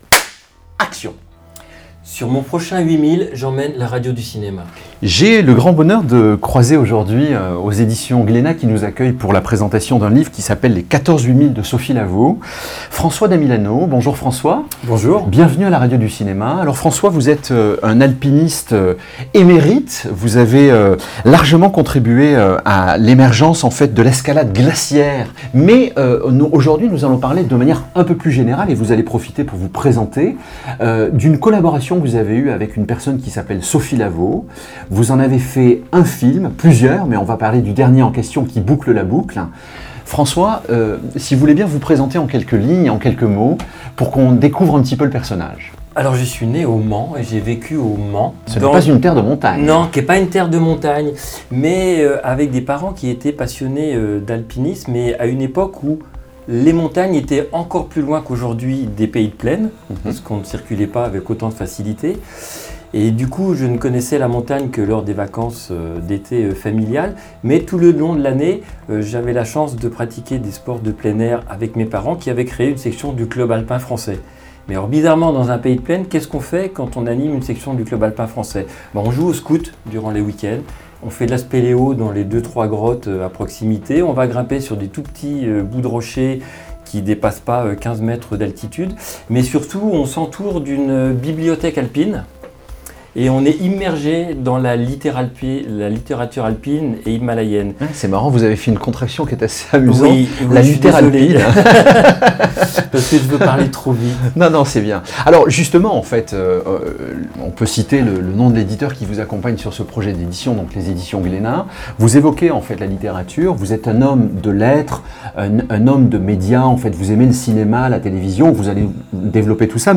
aux éditions Glénat à Paris.